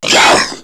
The way it sounds kind of growly, but also has that high-pitched quality to it.
The sample of the “Hyah!” sound: